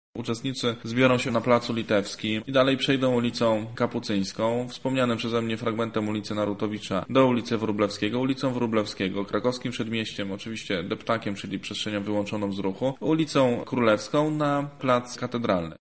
O trasie przemarszu mówi